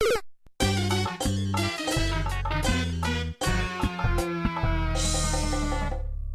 A death sound effect